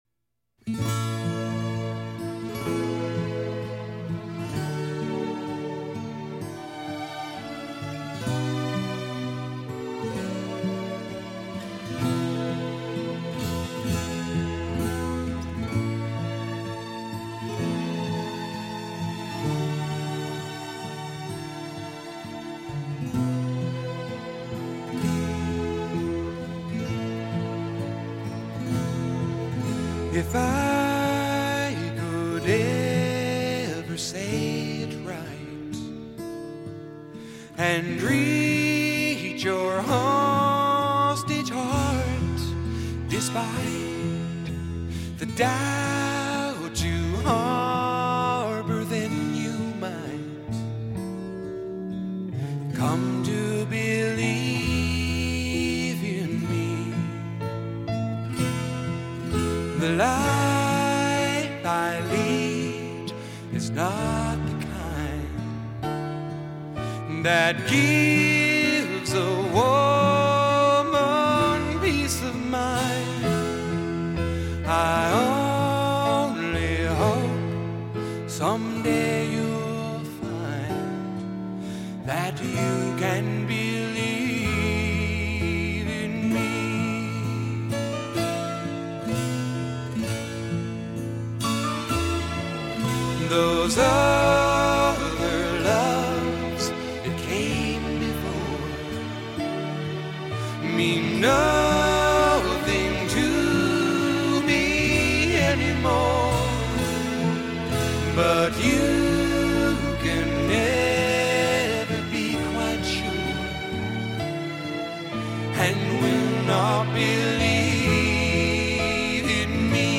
***[民谣诗人]***